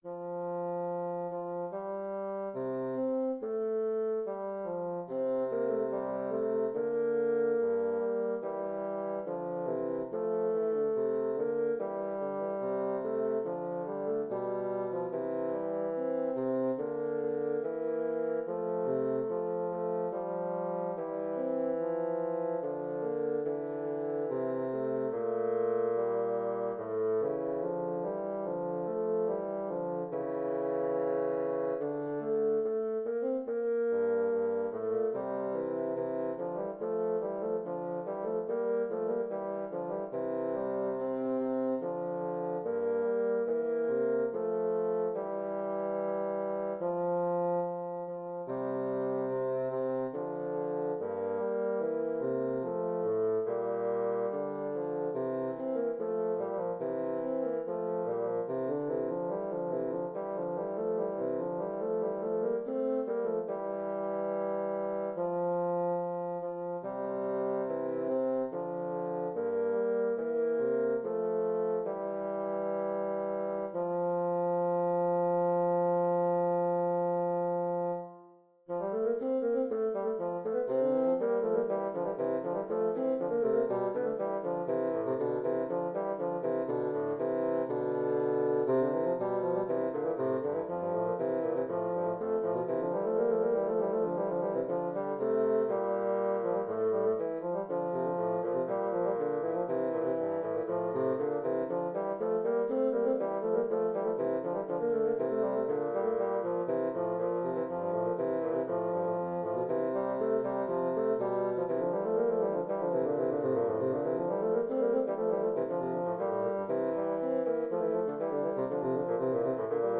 Intermediate bassoon duet
Instrumentation: Bassoon duet